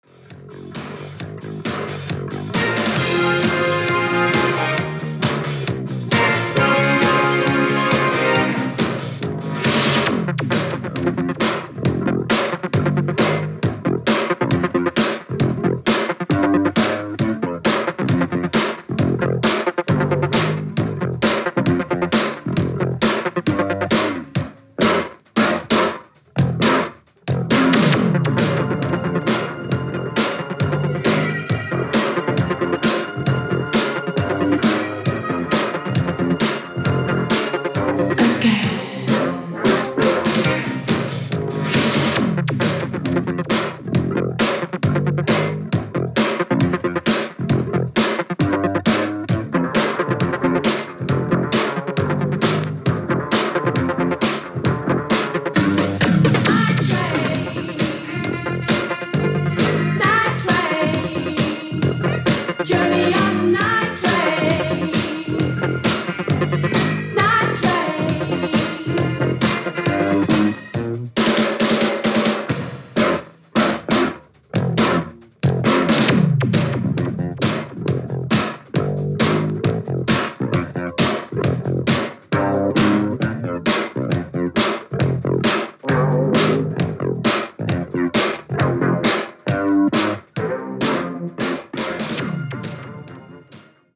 80's POP感もあってノリノリ！！！